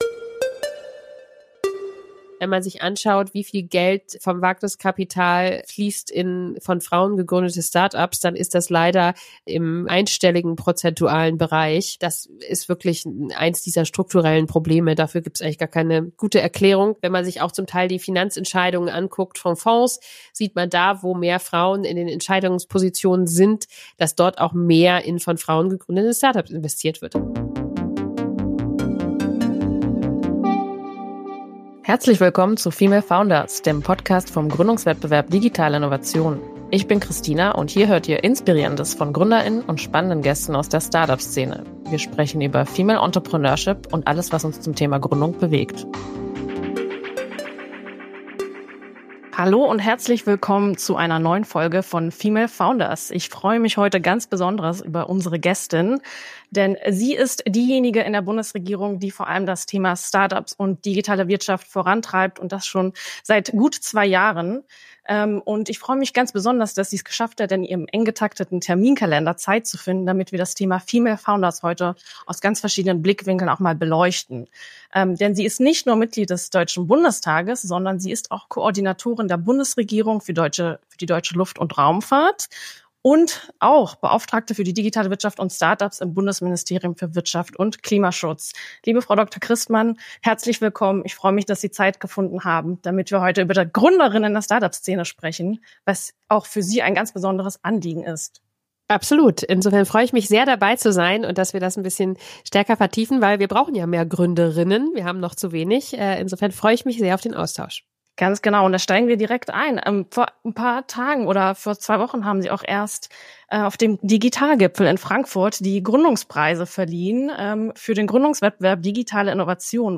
Mit welchen Maßnahmen die Diversität in Führungsteams sonst noch gefördert werden soll, warum mehr Frauen über Start-up Investitionen entscheiden sollten und wie das Gründen familienfreundlicher gestaltet werden kann, erfahrt ihr ebenfalls in unserem Interview!